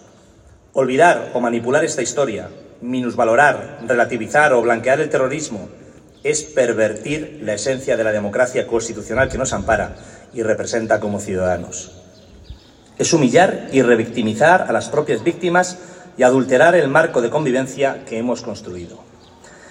Asimismo, Velázquez ha participado en la lectura de un manifiesto, acordado por unanimidad de todos los grupos con representación municipal, que recoge la voluntad de defender la memoria de Miguel Ángel Blanco, frente al olvido.
Cortes de voz
carlos-velazquez-manifiesto-2.m4a